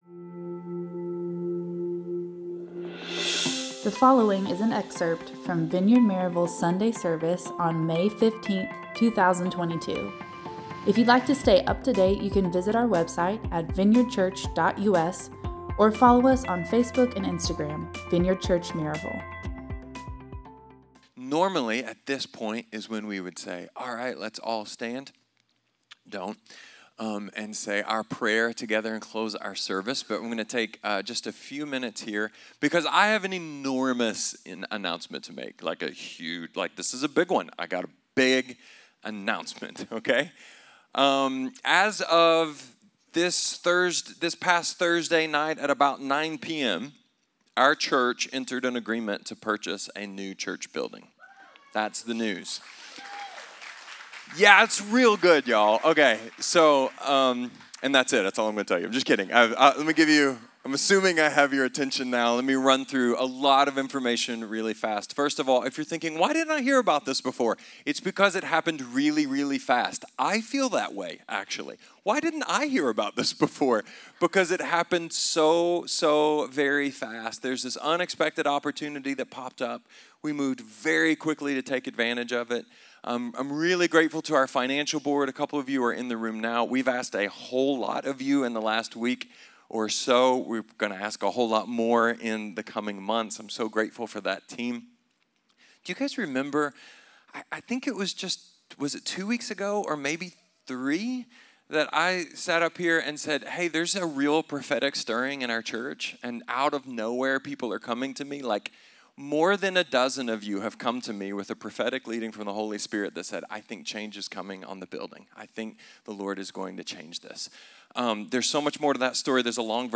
This is an excerpt from the Vineyard Maryville Sunday service on May 15, 2022.